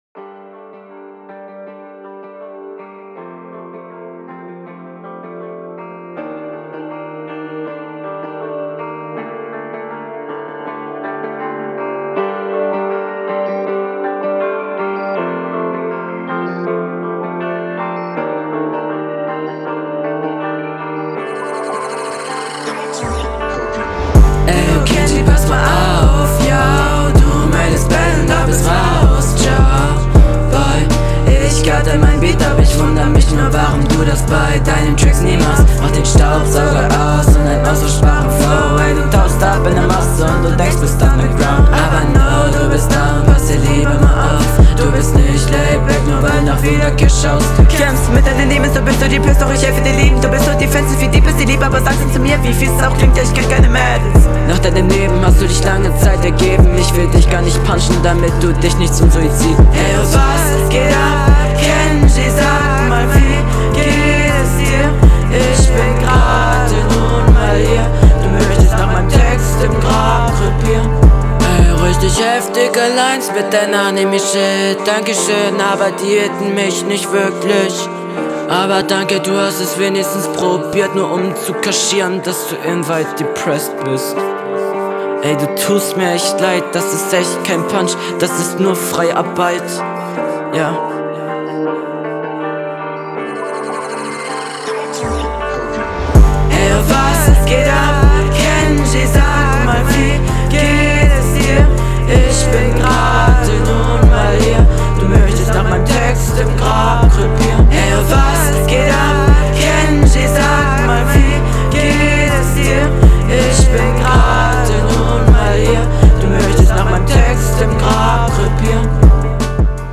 Yo die Doubles sind Asynchron, und glaube …
Deeper Beat okay.